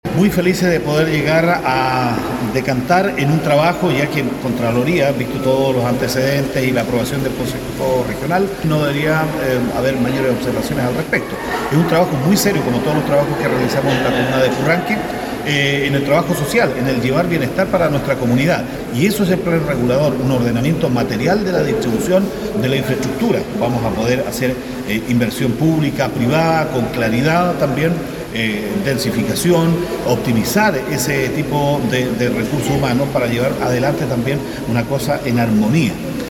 El alcalde de Purranque, César Crot Vargas, indicó que este es un trabajo serio que realiza la municipalidad y que será de gran beneficio para la comuna, atrayendo inversión pública y privada, armonizando los espacios para el beneficio de los vecinos y vecinas de la comuna.